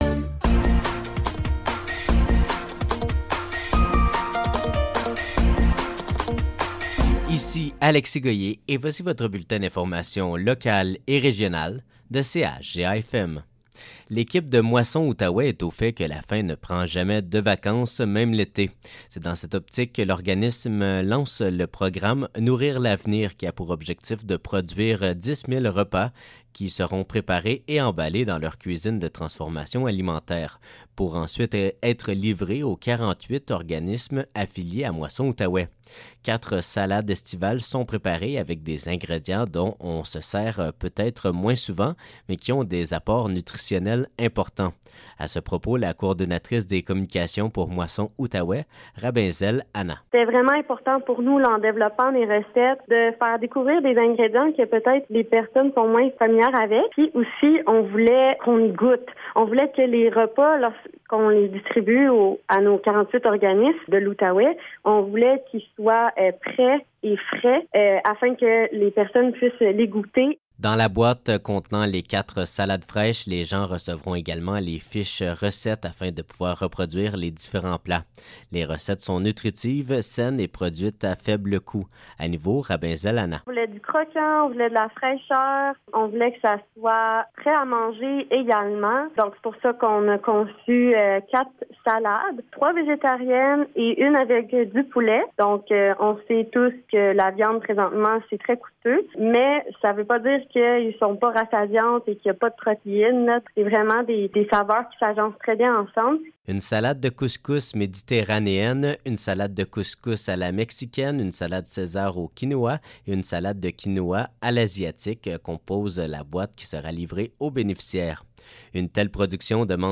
Nouvelles locales - 11 juillet 2024 - 12 h